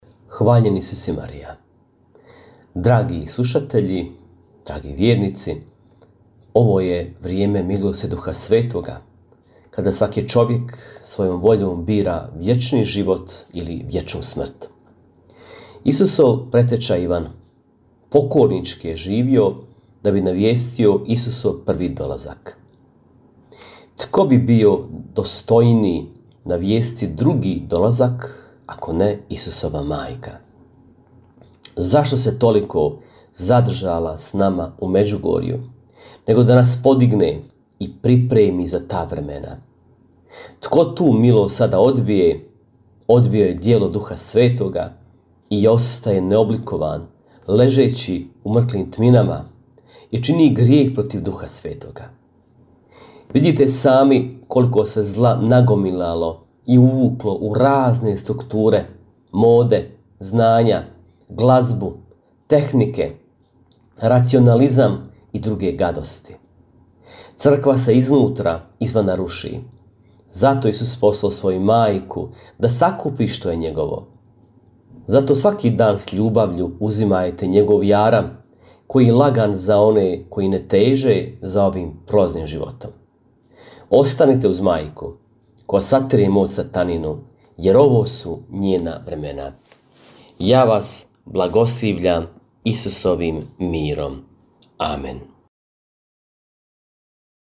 Emisije priređuju svećenici i časne sestre u tjednim ciklusima.